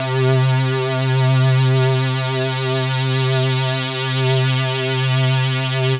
Index of /90_sSampleCDs/Trance_Explosion_Vol1/Instrument Multi-samples/Angry Trance Pad
C4_angry_trance_pad.wav